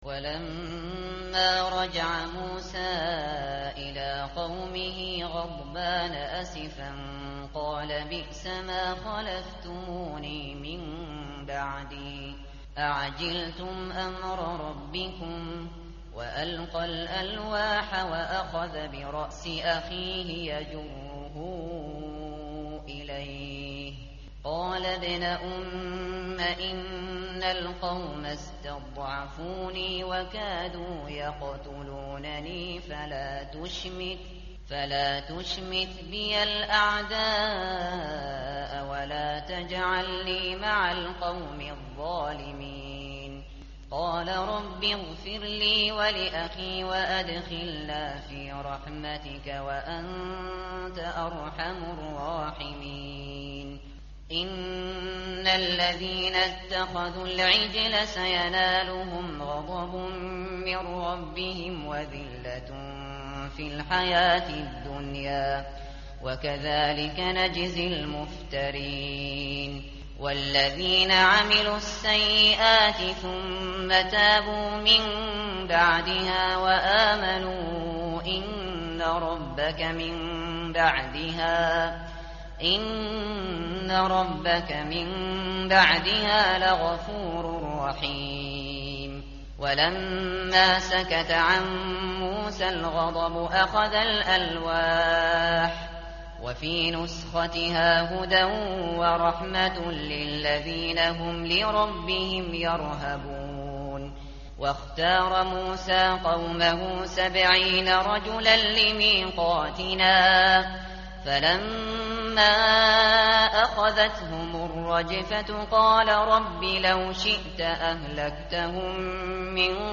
متن قرآن همراه باتلاوت قرآن و ترجمه
tartil_shateri_page_169.mp3